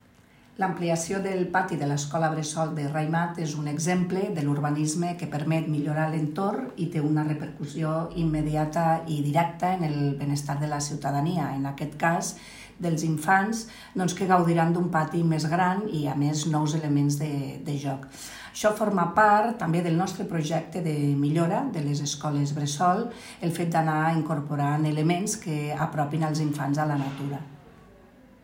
Fitxers relacionats Tall de veu de la tinenta d'alcalde i regidora d'Agenda Urbana i Espai Agrari, Begoña Iglesias (429.6 KB) Tall de veu del regidor de Joventut, Educació i Ocupació, Xavier Blanco (262.1 KB)